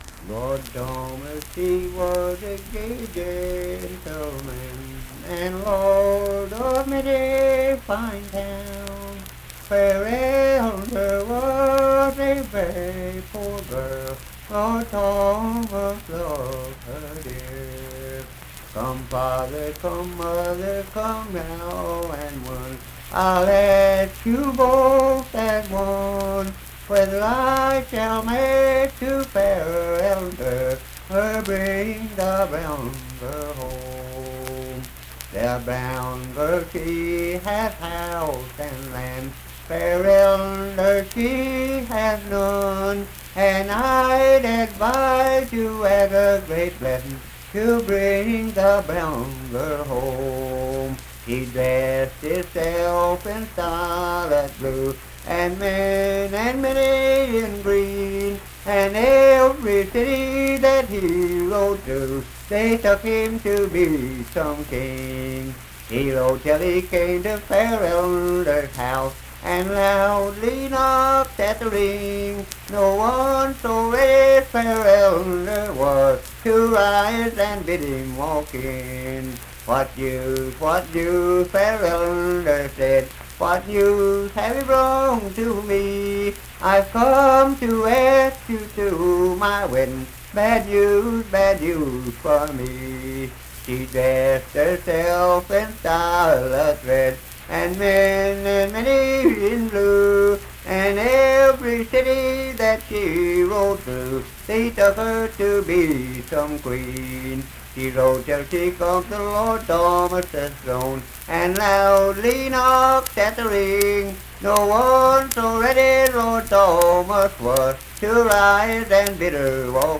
Unaccompanied vocal and banjo music
Voice (sung)